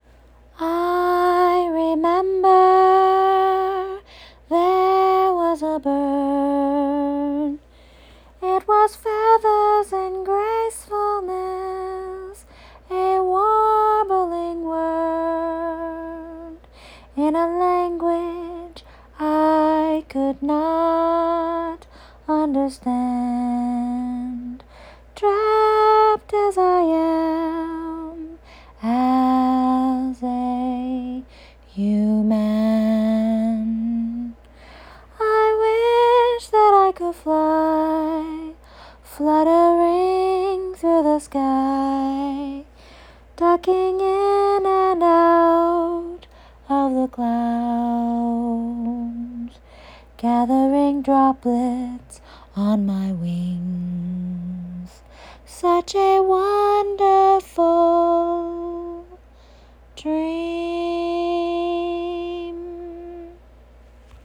AUDIO: singing poetry
I know I’m pitchy.
Fantasy-slow.m4a